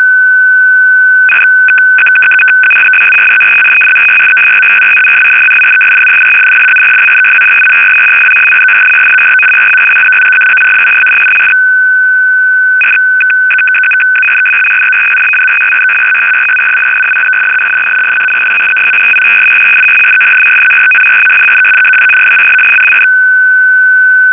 • Russian Intelligence 200bd 1000Hz FSK one-way error-correcting broadcast system used by one of the Russian intelligence agencies for the delivery of messages to their operatives abroad on fixed schedules: